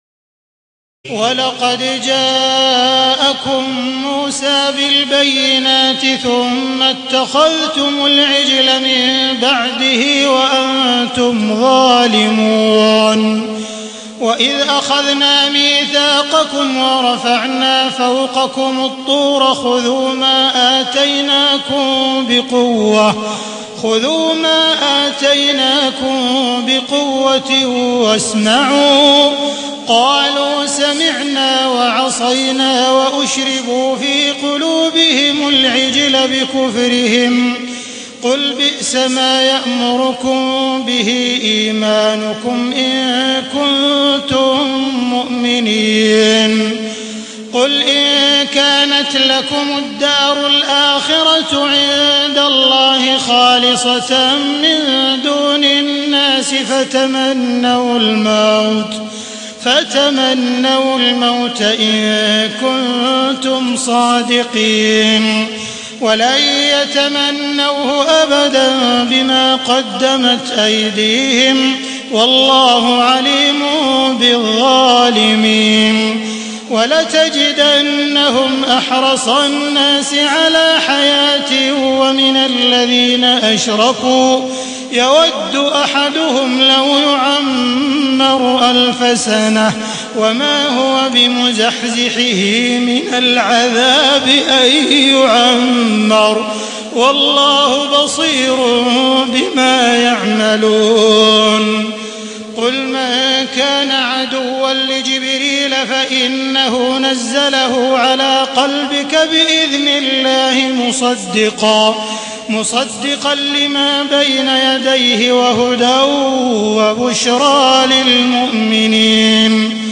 تهجد ليلة 21 رمضان 1432هـ من سورة البقرة (92-141) Tahajjud 21 st night Ramadan 1432H from Surah Al-Baqara > تراويح الحرم المكي عام 1432 🕋 > التراويح - تلاوات الحرمين